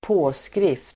Ladda ner uttalet
påskrift substantiv (även om namnteckning), text [also " signature"] Uttal: [²p'å:skrif:t] Böjningar: påskriften, påskrifter Synonymer: namnteckning, signatur, underskrift Definition: något som står skrivet på något